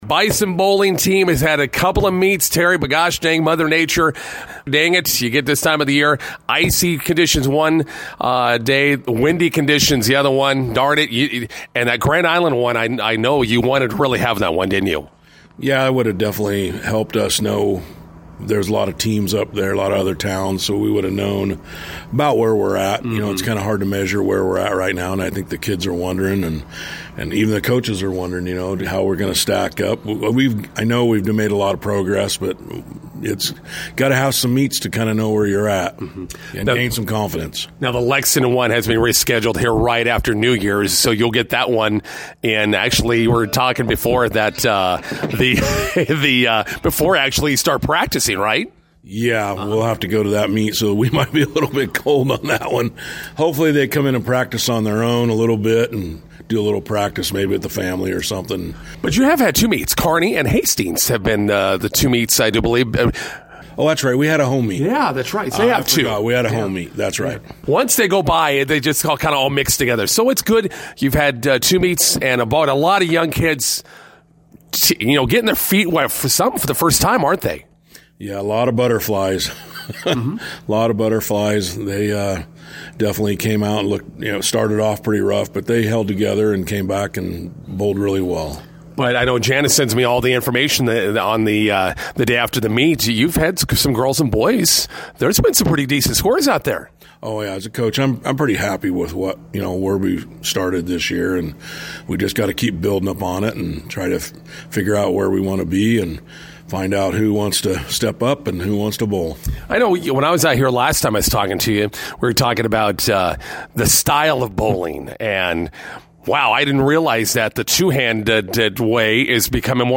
INTERVIEW: Bison girls/boys bowling kicks off their season with two meets.